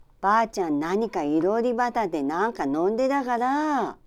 Aizu Dialect Database
Type: Statement
Final intonation: Falling
Location: Aizuwakatsu/会津若松市
Sex: Female